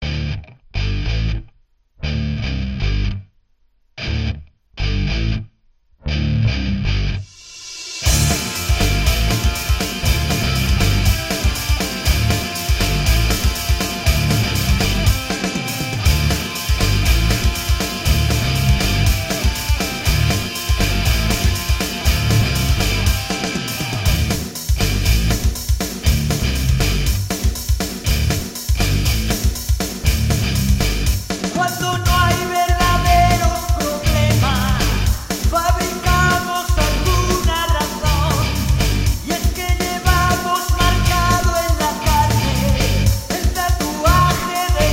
Pop / Rock